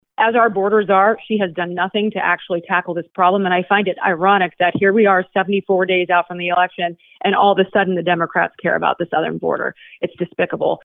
Hinson made her comments during her weekly conference call with reporters and says Harris’s stance on the southern border had been virtually nonexistent before the convention.